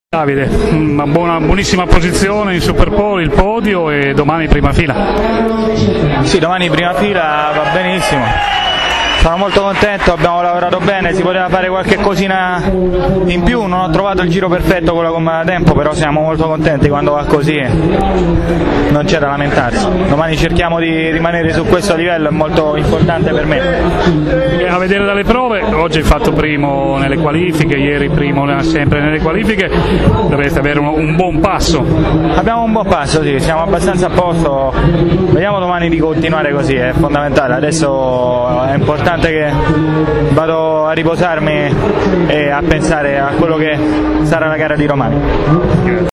ascolta la sua intervista) dopo aver terminato in testa le due qualifiche di ieri e di questa mattina, conferma di amare questa pista e conquista la prima fila.